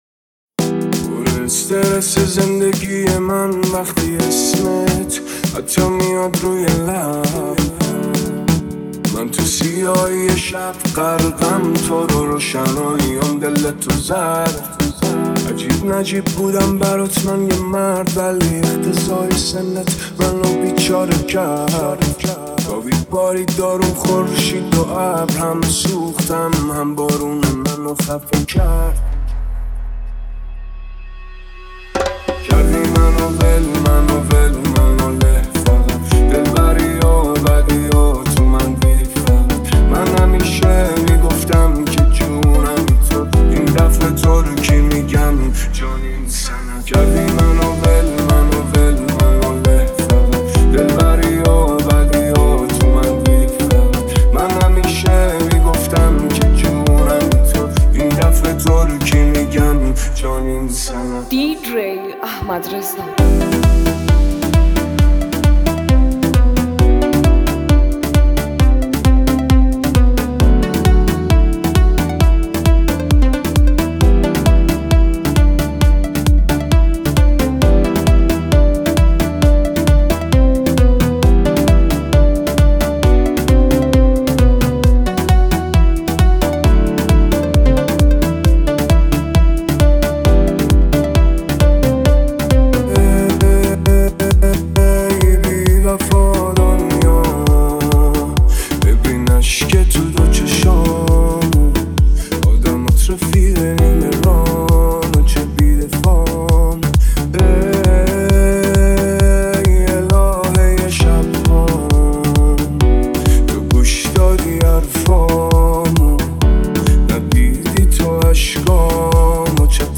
اصلی + ریمیکس تند بیس دار ترکیبی رپ رپی